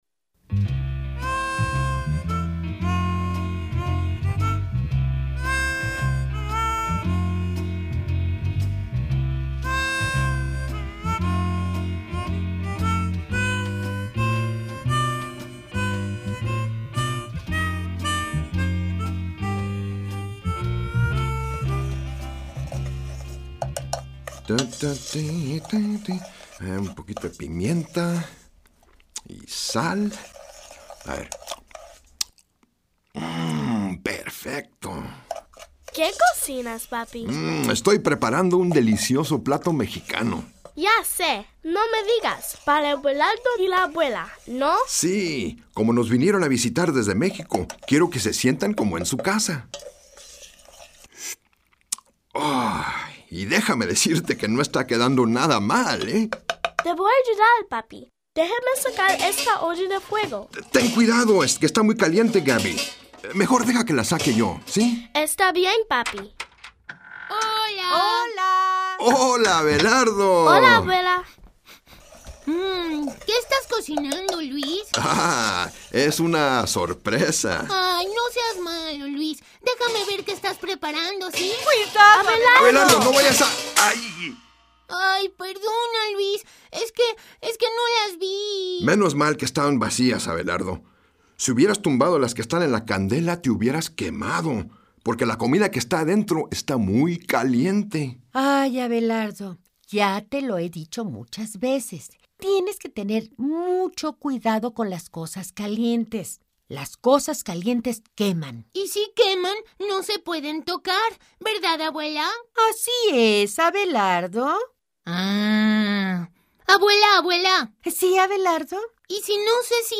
Songs and stories